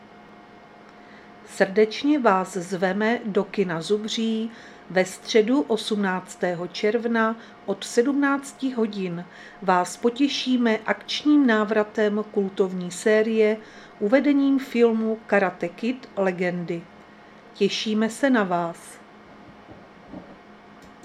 Záznam hlášení místního rozhlasu 18.6.2025
Zařazení: Rozhlas